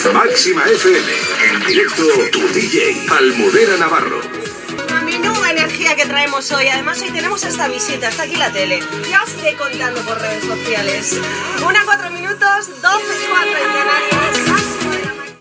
Indicatiu de la presentadora, comentari, hora